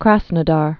(krăsnə-där, krə-snə-där)